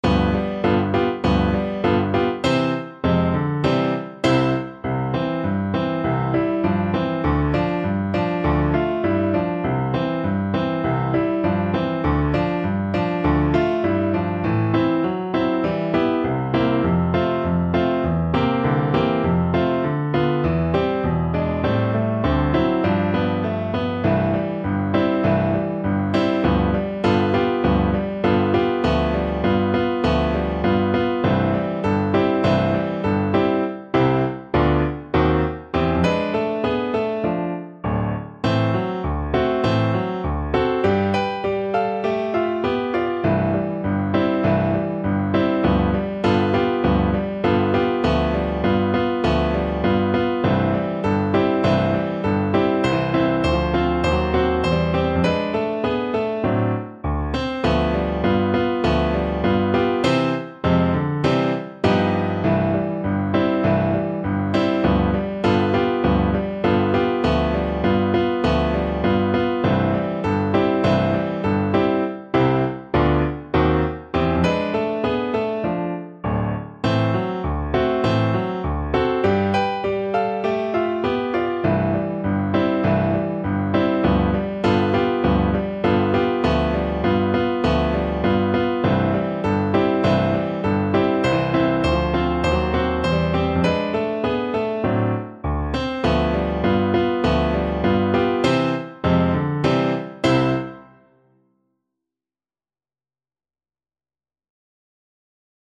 Moderato =c.100